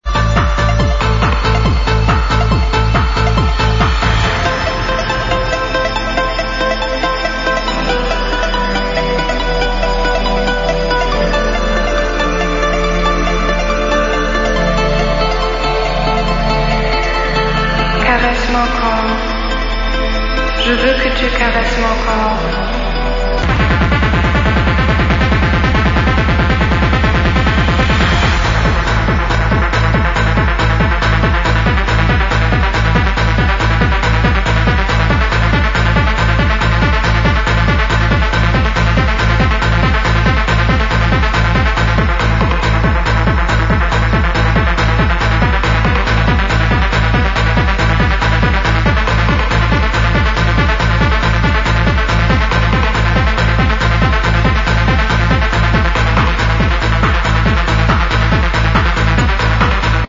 Tune With French Vocals